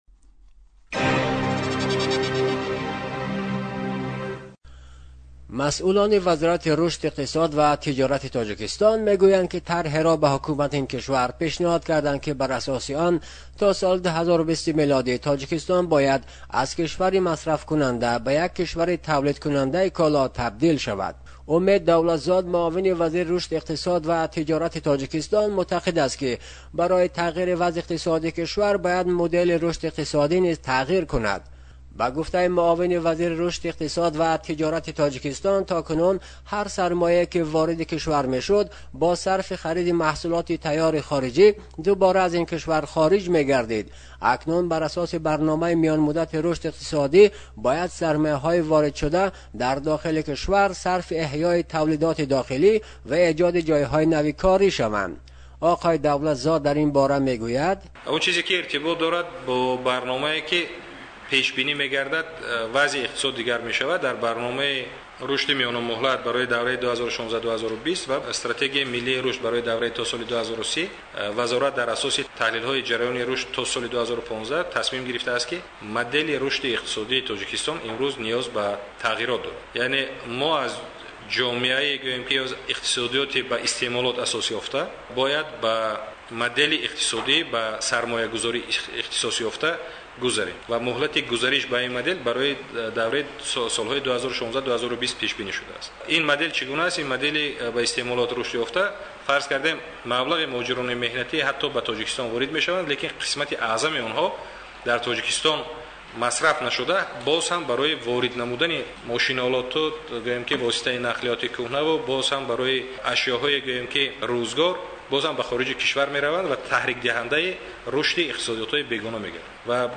Tue, 02 Aug 2016 16:07:30 GMT (last modified Tue, 02 Aug 2016 16:07:30 GMT ) by Pars Today Ахбор / Тоҷикистон Роҳи беҳбуди вазъи иқтисоди Тоҷикистон чист?